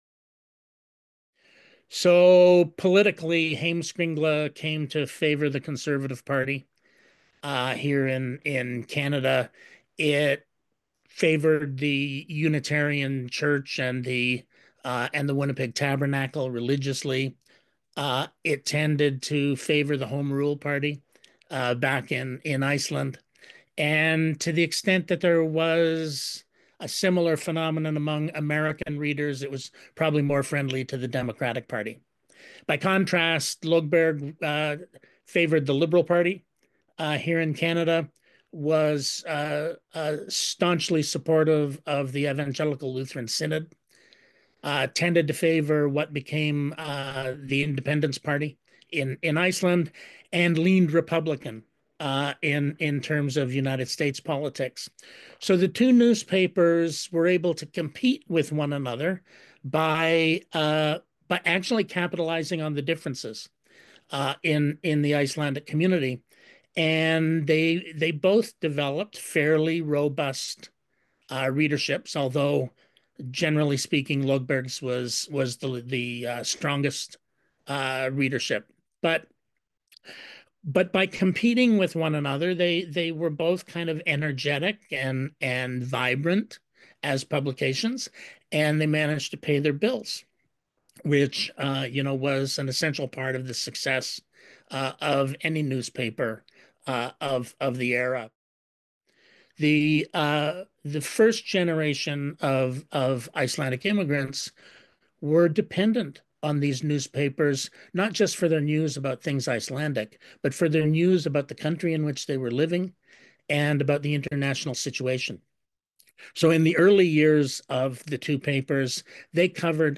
SJ-interview-clip-2.mp3